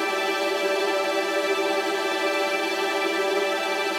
Index of /musicradar/gangster-sting-samples/Chord Loops
GS_TremString-G6+9.wav